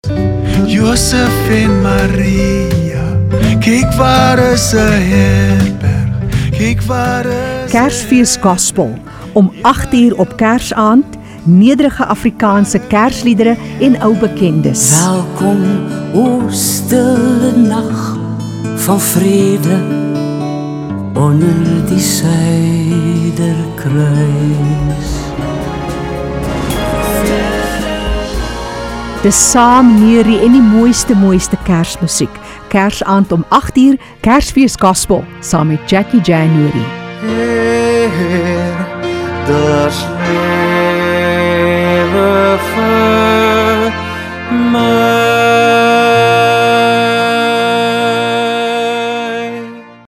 20:05 Dis Gospel vir Kersfees (Eenvoudige Afrikaanse en ander ou bekende gospel kersliedere van hoop, geloof en vrede).
Daar is ou bekendes vir saam-neurie –  en sommige wat ietwat afdwaal van die bekende wysie.